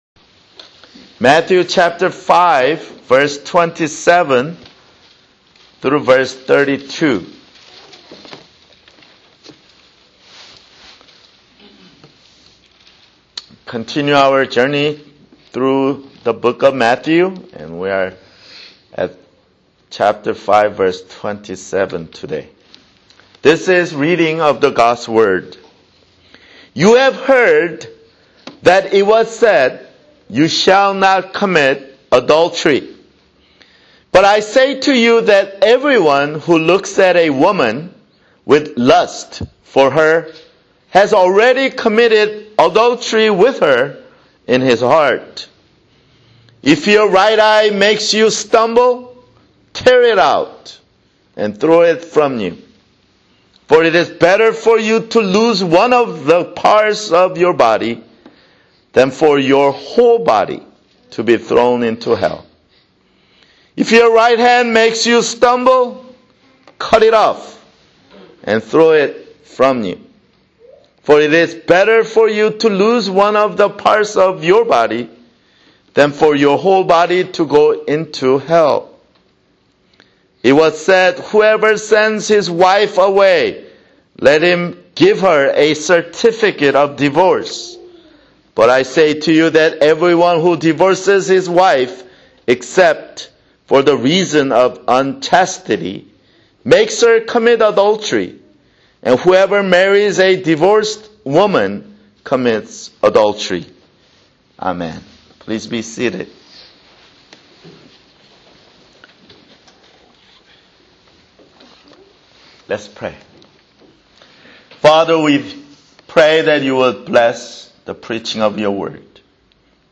[Sermon] Matthew (26)